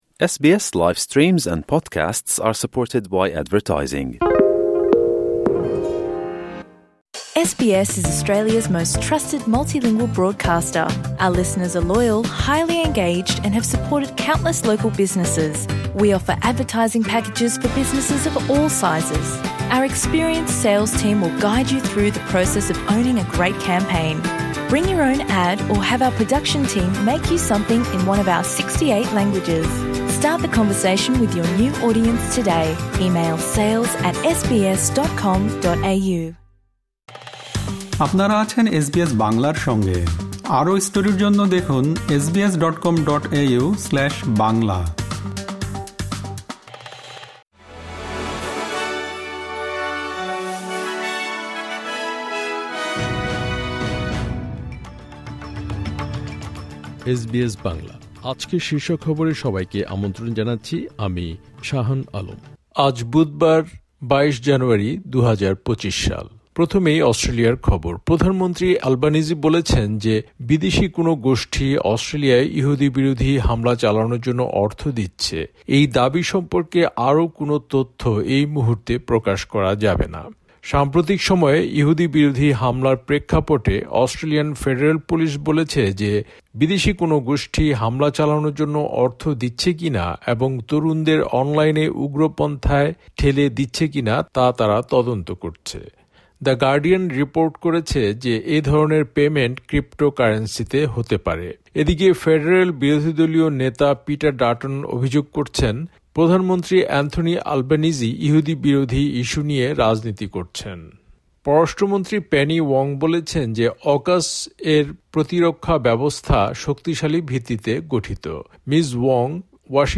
এসবিএস বাংলা শীর্ষ খবর: ২২ জানুয়ারি, ২০২৫